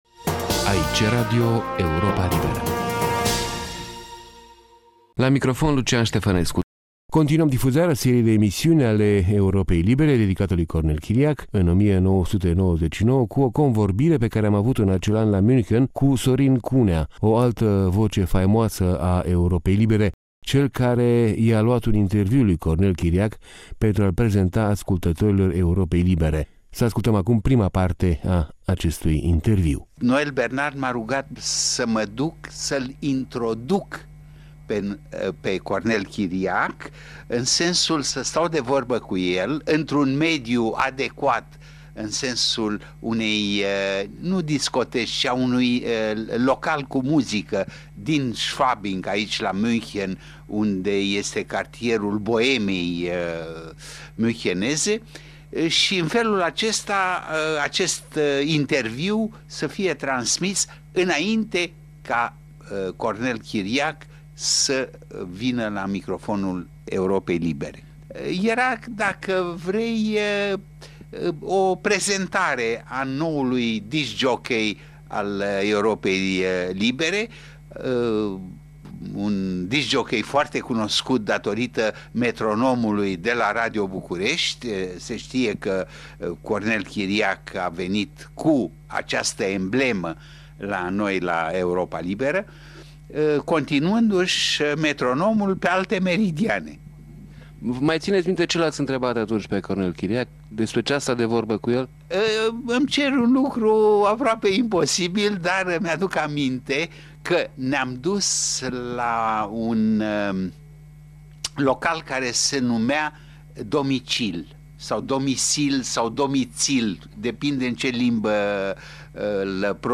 într-un interviu realizat în 1999